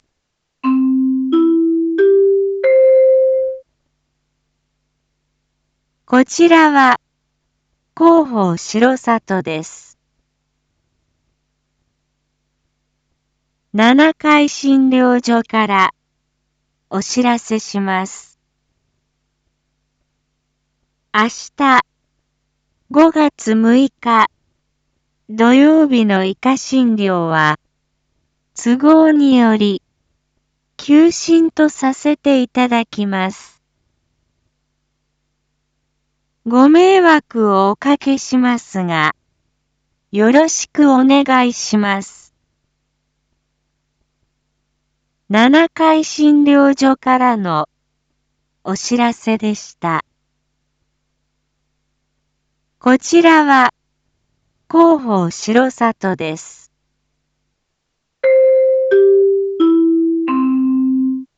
Back Home 一般放送情報 音声放送 再生 一般放送情報 登録日時：2023-05-05 19:01:02 タイトル：R5.5.5 19時放送分 インフォメーション：こちらは広報しろさとです。